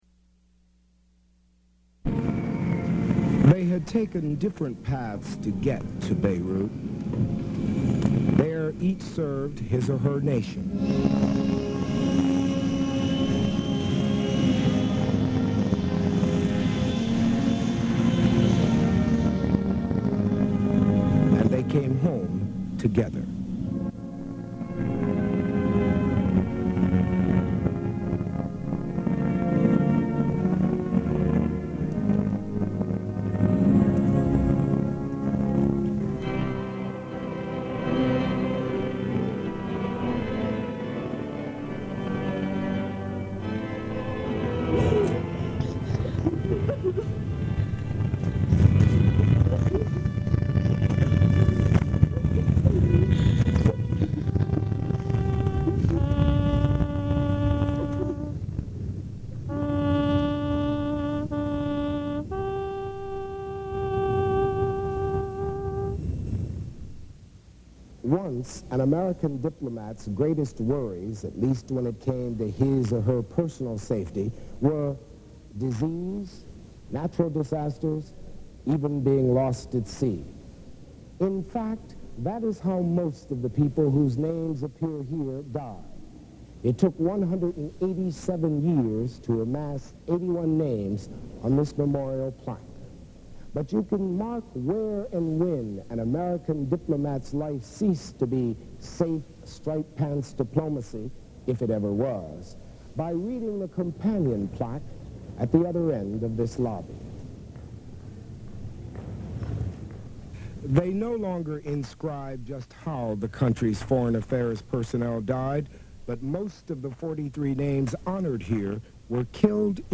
Broadcast on CBS-TV, May 1, 1983.